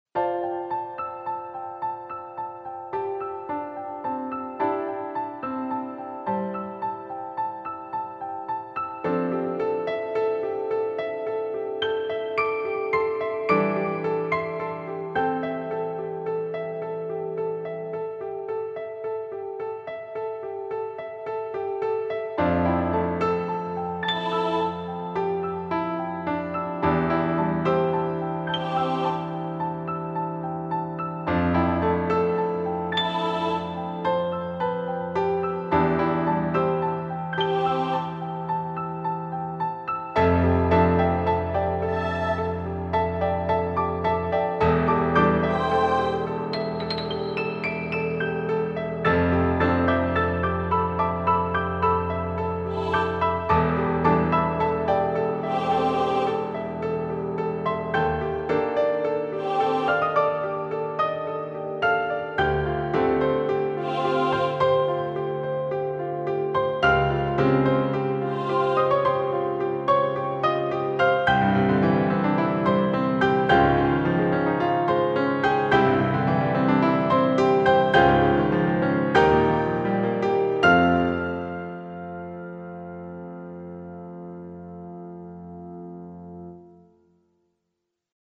落ち着いたピアノ連弾曲。